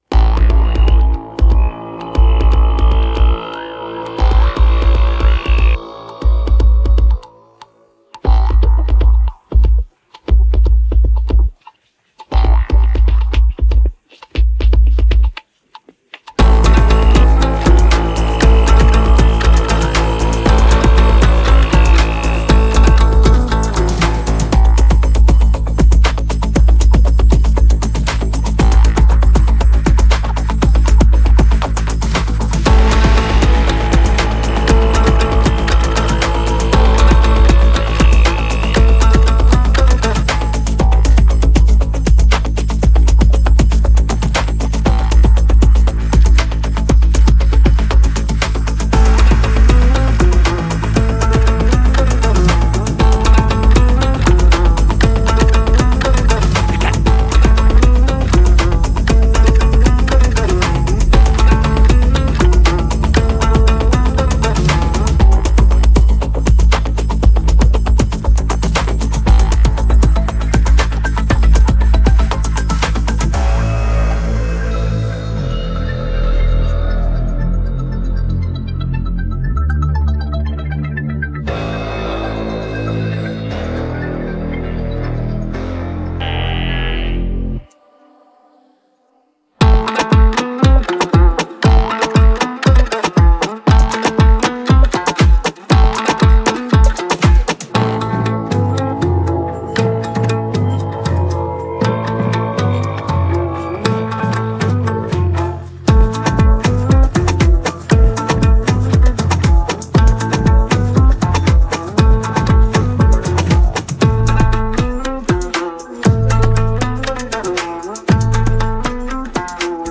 Качественный минус и текст песни